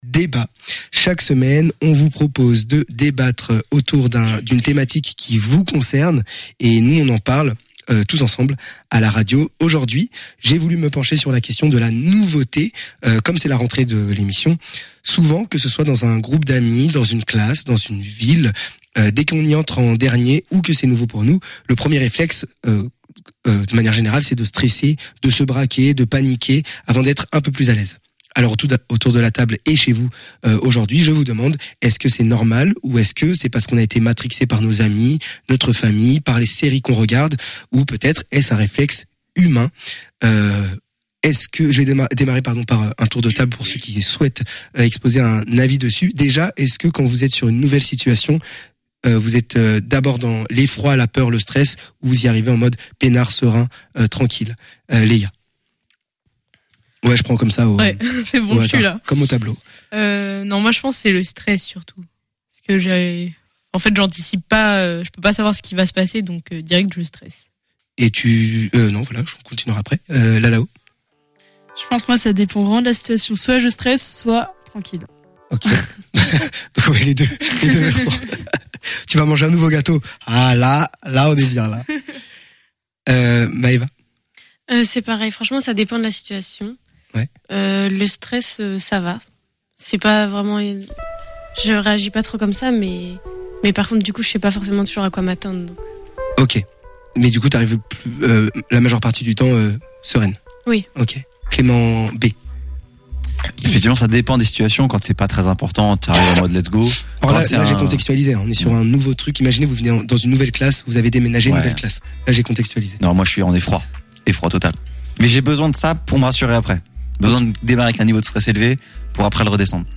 Nouvelle semaine, nouveau débat.
012_le_debat_de_la_semaine.mp3